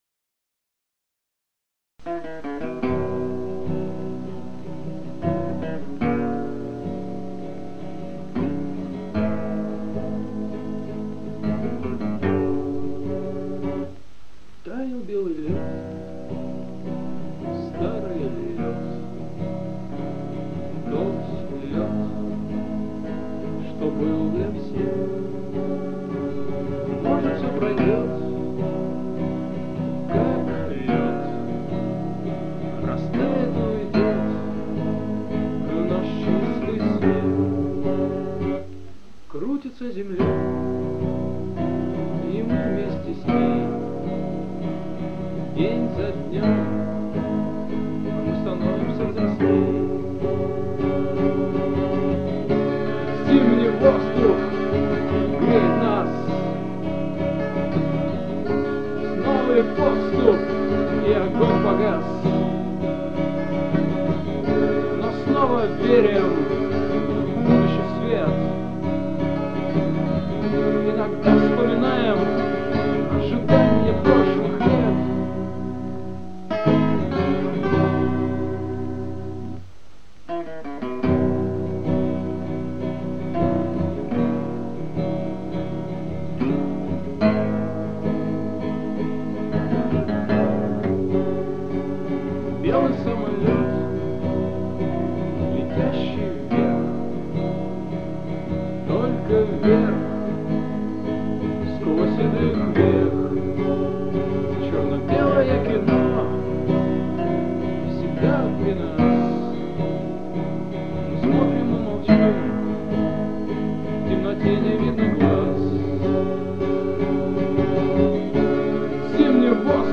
Акустика
вокал, гитара
перкуссия
альт